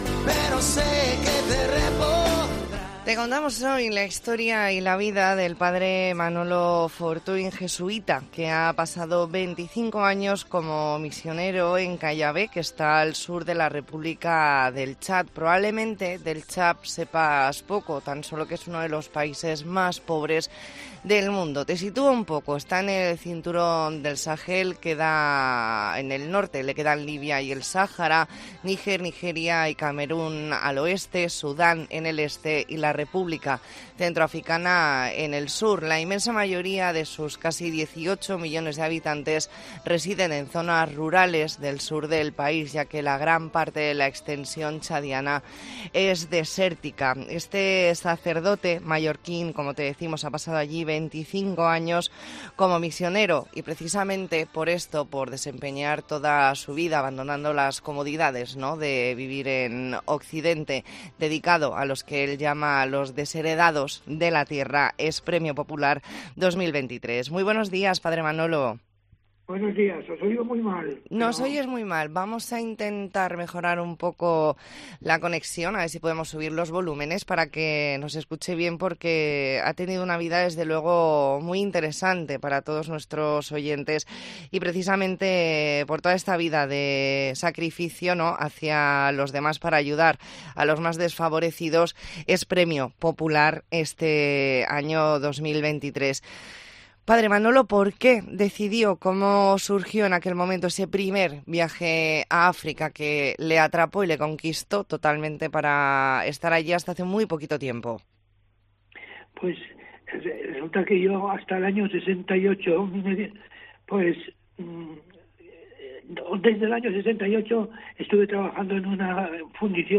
Entrevista en La Mañana en COPE Más Mallorca, martes 21 de noviembre de 2023.